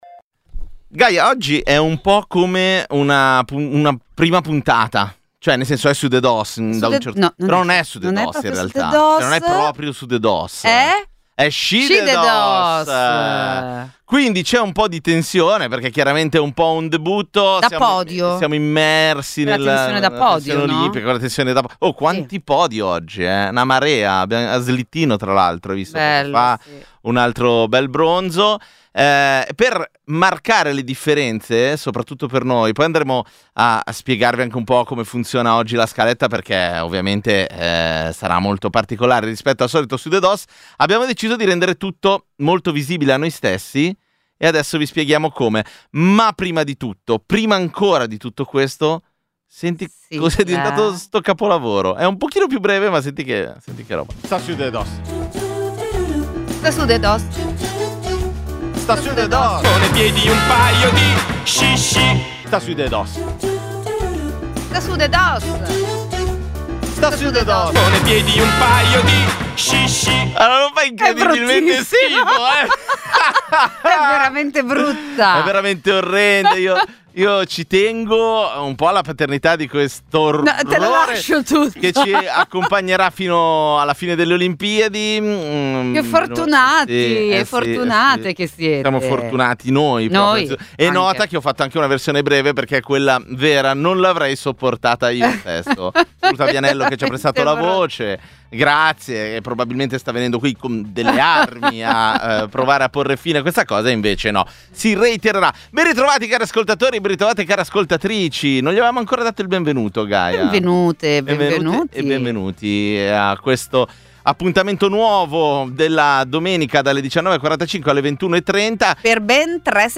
Sudedoss è il programma di infotainment che ogni domenica sera dalle 19.45 alle 21.30 accompagna le ascoltatrici e gli ascoltatori di Radio Popolare con leggerezza, ironia e uno sguardo semiserio sull’attualità.